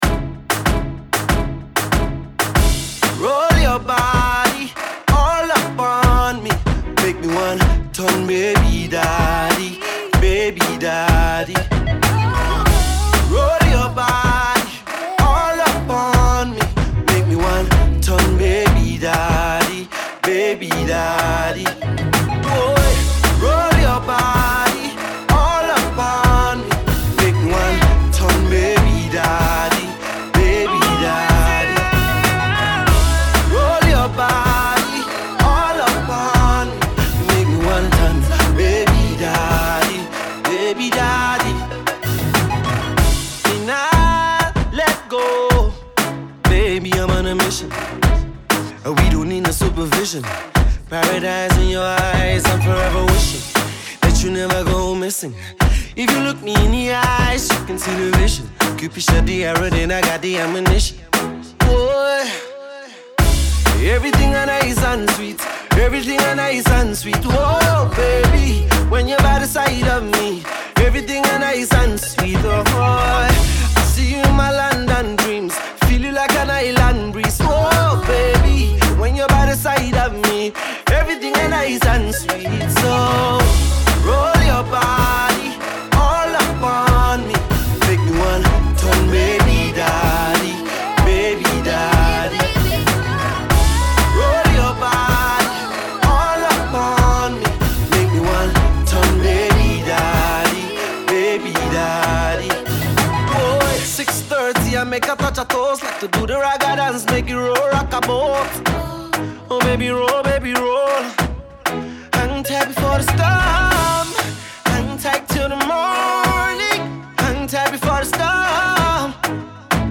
A More mid-tempo sensual groove